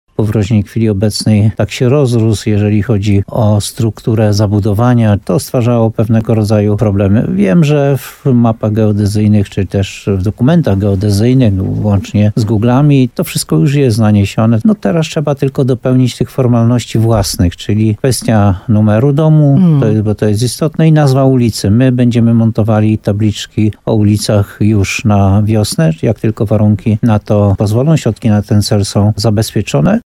Szczególnie ważne jest też przekazanie zmienionego adresu do pracodawcy, a w przypadku emerytów i rencistów do ZUS-u. Burmistrz Jan Golba podkreśla, że usystematyzowanie nazewnictwa było już konieczne.